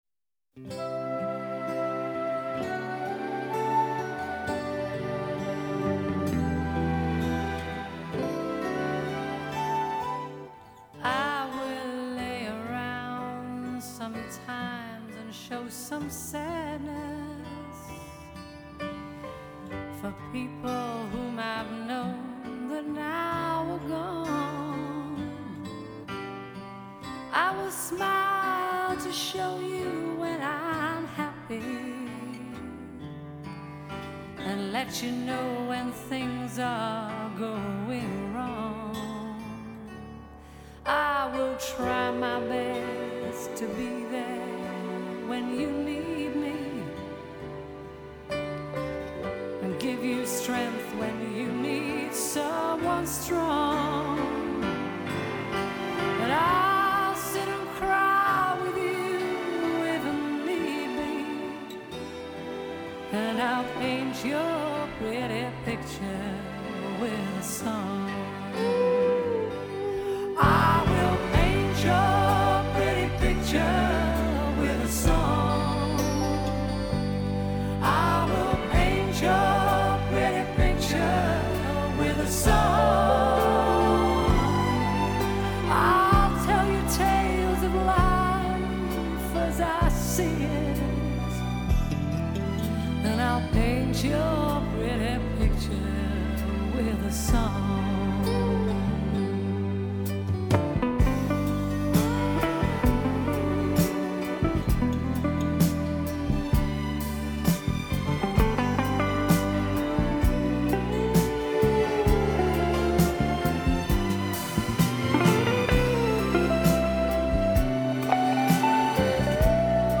прекрасные и мелодичные песни!